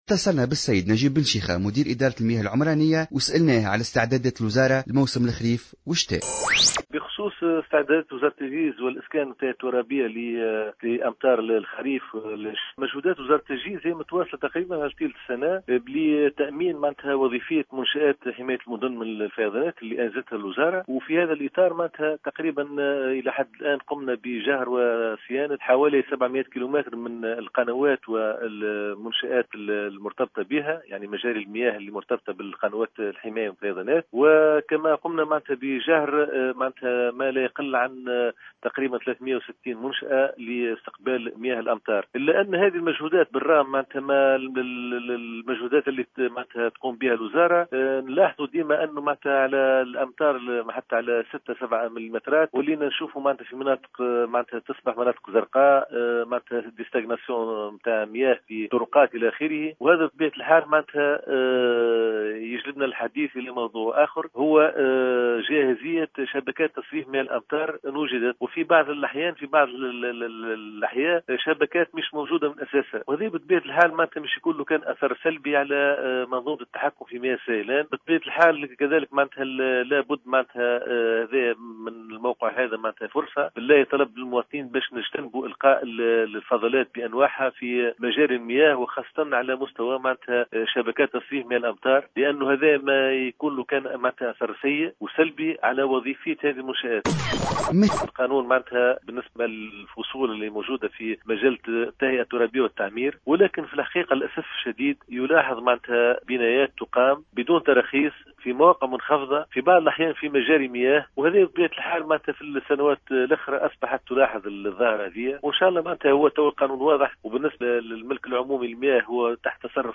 وأكد في تصريح لبرنامج "صباح الورد" على "الجوهرة أف أم" ضرورة تجنب القاء الفضلات في مجاري المياه وشبكات صرف مياه الأمطار لأن آثار هذا السلوك سيكون سلبيا على جاهزية الشبكات، بحسب تعبيره.